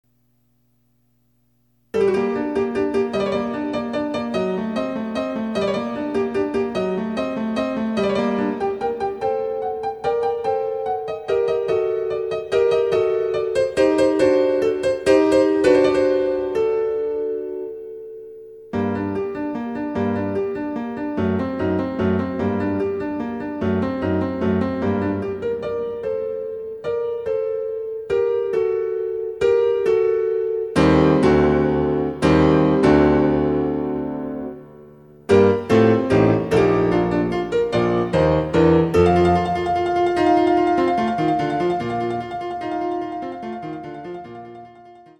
★フルートの名曲をピアノ伴奏つきで演奏できる、「ピアノ伴奏ＣＤつき楽譜」です。
試聴ファイル（伴奏）